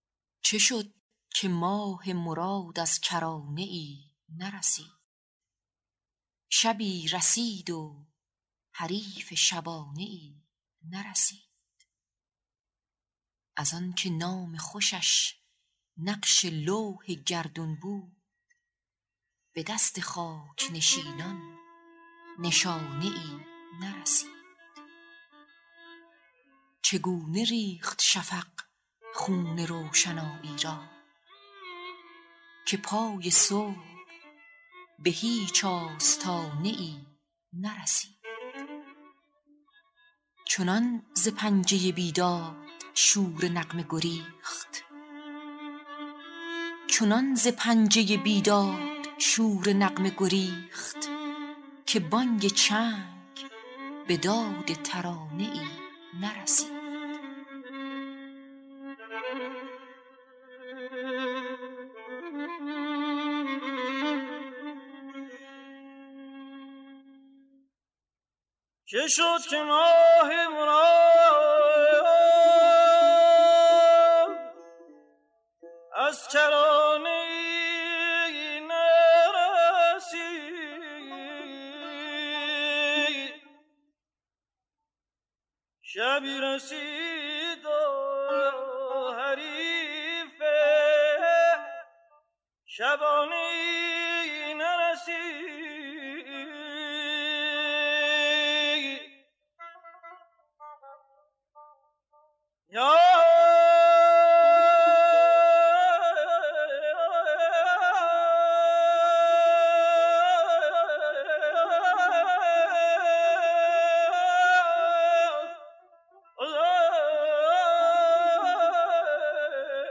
دستگاه:سه گاه
موسیقی اصیل ایرانی